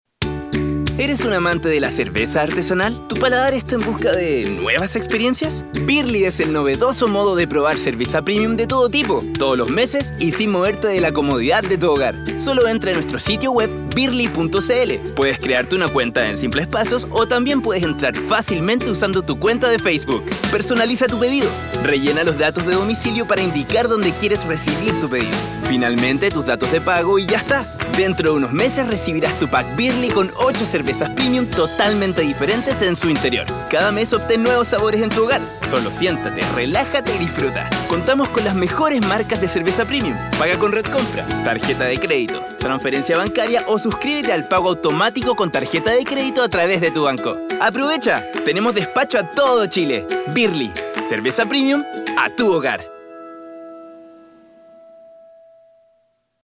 Tengo una fresca, muy versatil y original al momento de grabar.
Sprechprobe: Industrie (Muttersprache):
A young/middle spanish voice who's an Actor and very creative at work time looking for Original ideas.